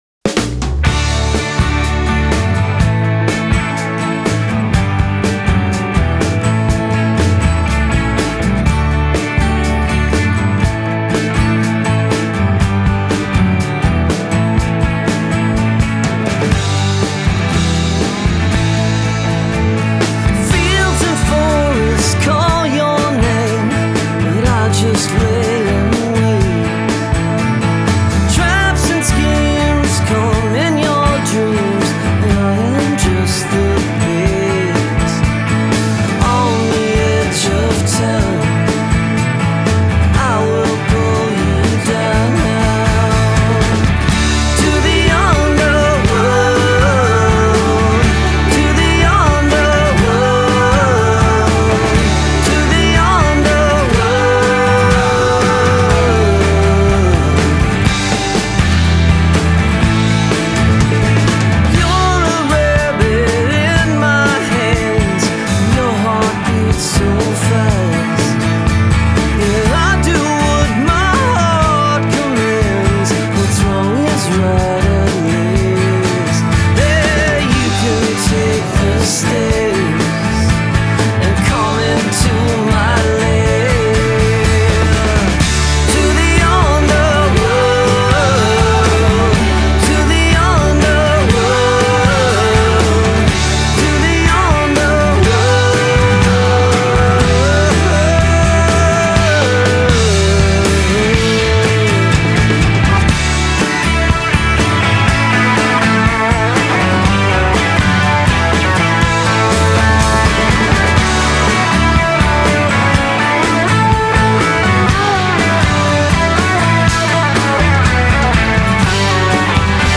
the song oozes classic